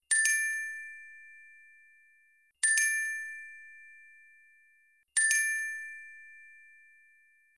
pomodoro-sound.wav